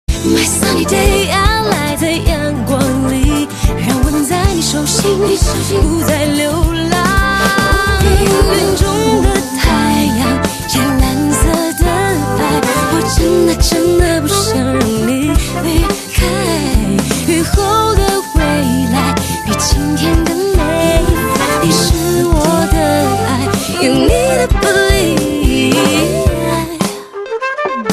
M4R铃声, MP3铃声, 华语歌曲 65 首发日期：2018-05-15 13:59 星期二